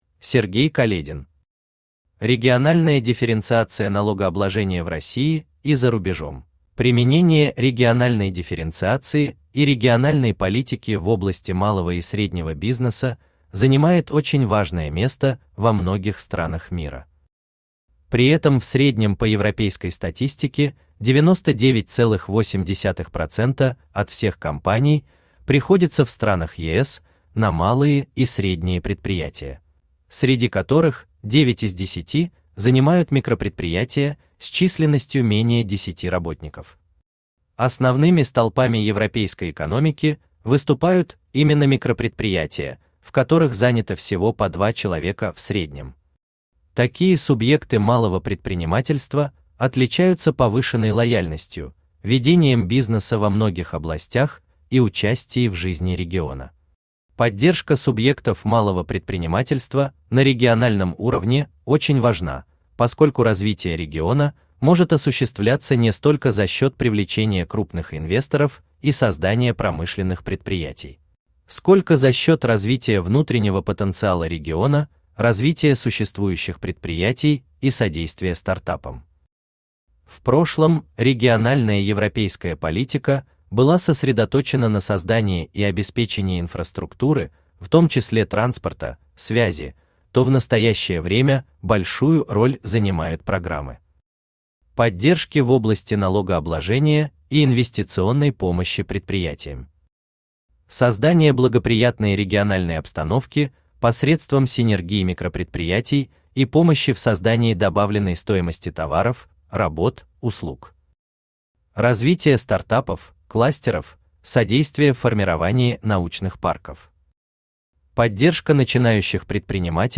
Aудиокнига Региональная дифференциация налогообложения в России и за рубежом Автор Сергей Каледин Читает аудиокнигу Авточтец ЛитРес.